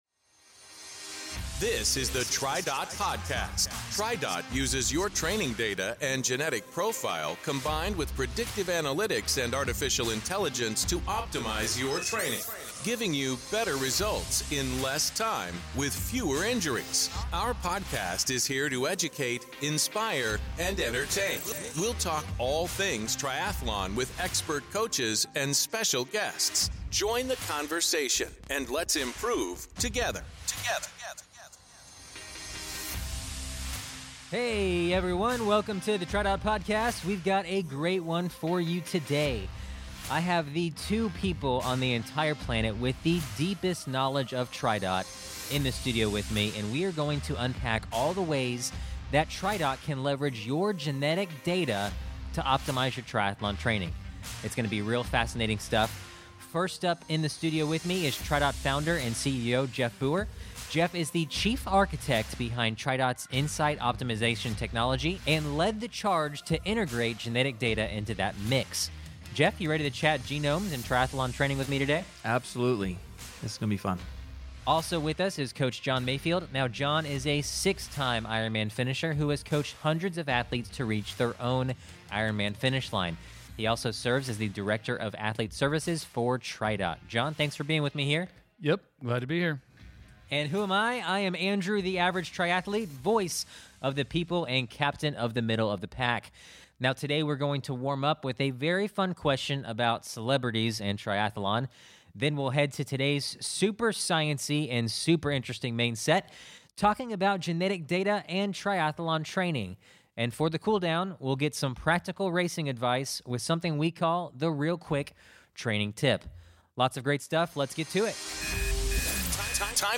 0:37.0 We've got a great one for you today. I have the two people on the entire planet with the deepest knowledge of Tridot in the studio with me, and we are going to unpack all the ways that Tridot can leverage your genetic data to optimize your triathlon training.